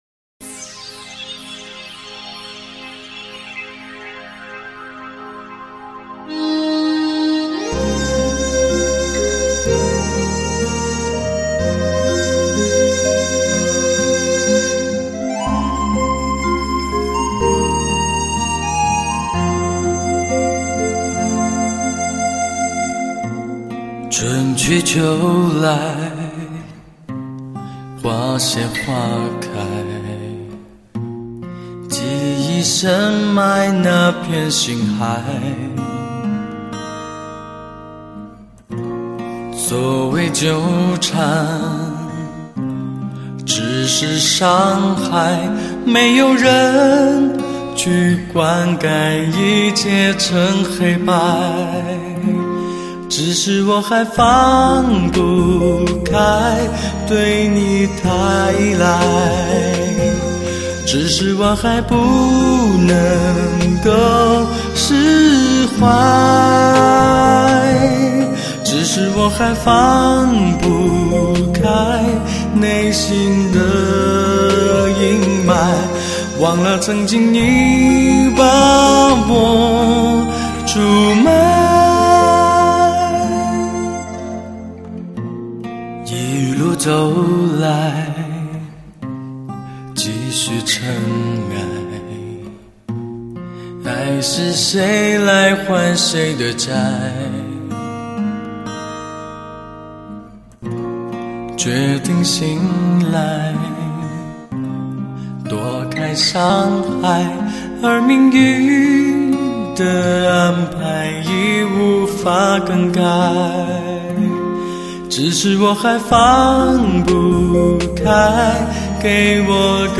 非同凡响的人声音色，车载
五彩韵色中。清新音色，磁性迷人歌喉，一声声，一首